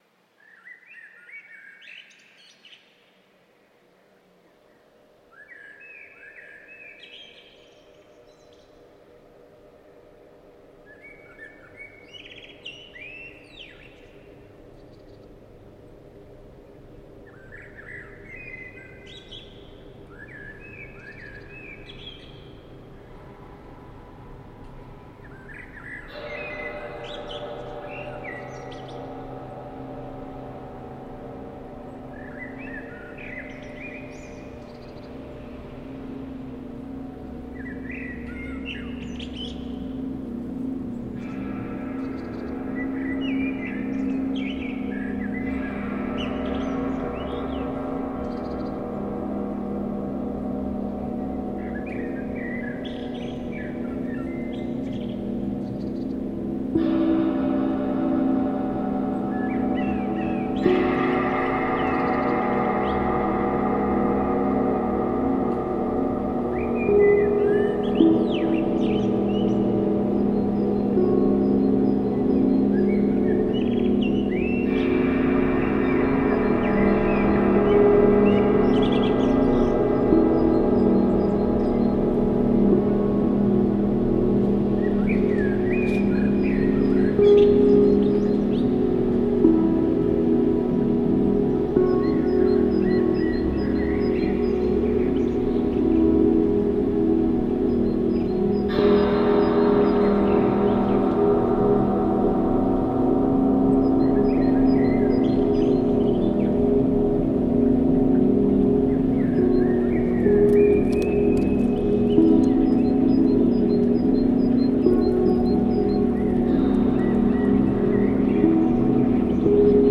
soundscape.Dw2gr36U.mp3